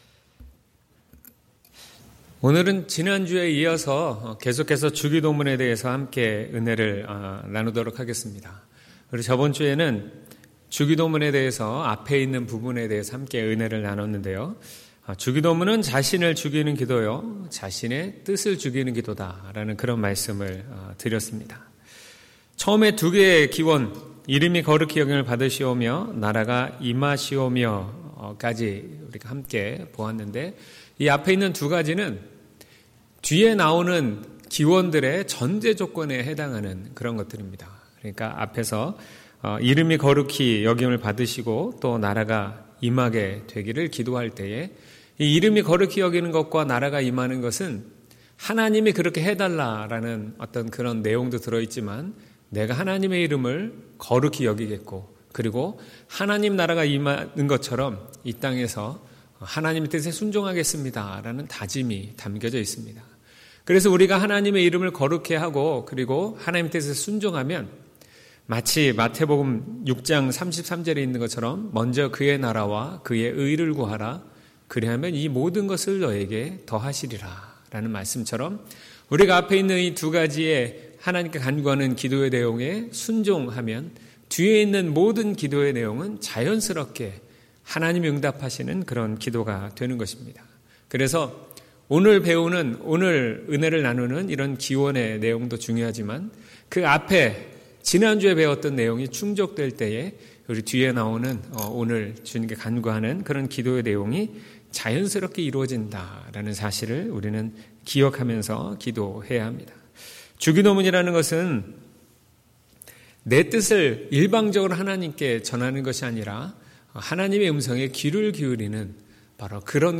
2월 3일 주일설교/주님이 가르쳐주신 기도2/마6:9-13